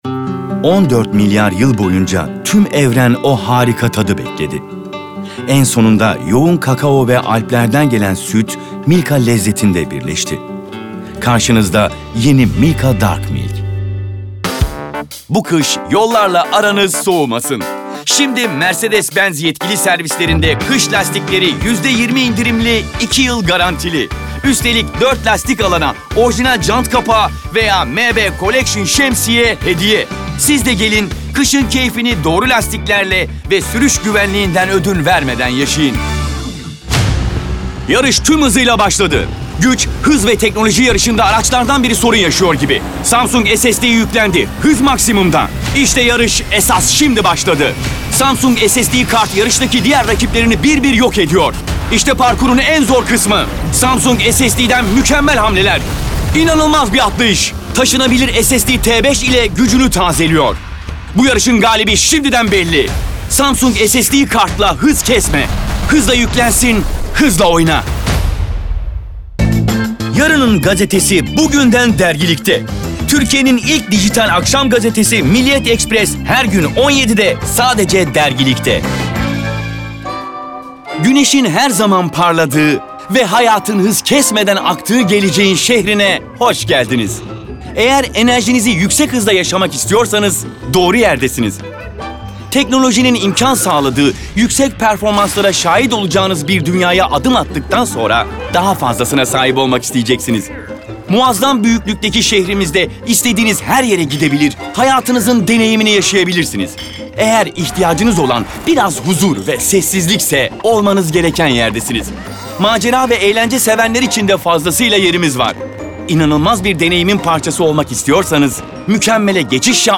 Erkek
DEMO SESLERİ
Belgesel, Eğlenceli, Fragman, Güvenilir, Havalı, Karakter, Seksi, Animasyon, Karizmatik, Film Sesi, Tok / Kalın, Dış Ses, Dostane,